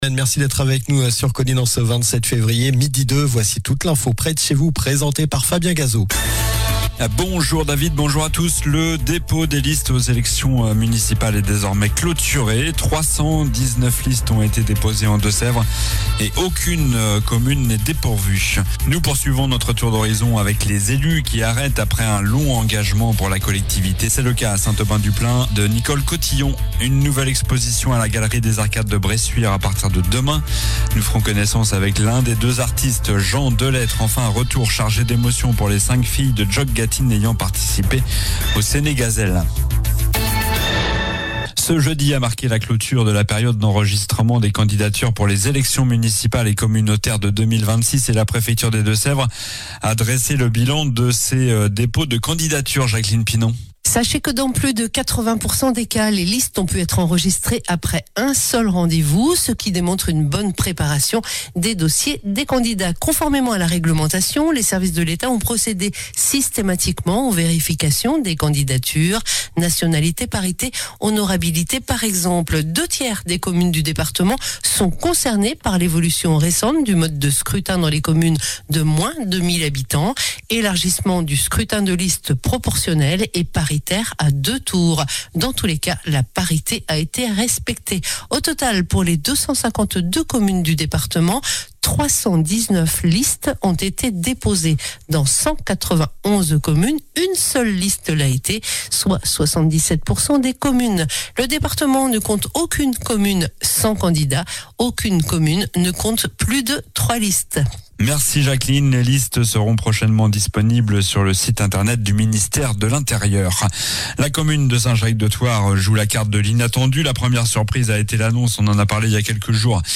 Journal du vendredi 27 février (midi)